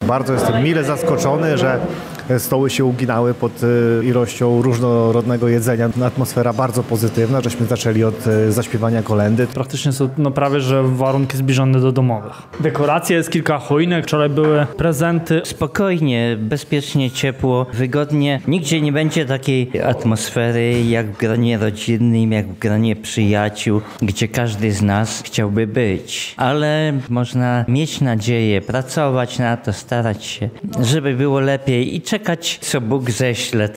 Zaczęliśmy od zaśpiewania kolędy – mówią uczestniczący we wspólnym śniadaniu.